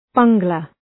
{‘bʌŋglər}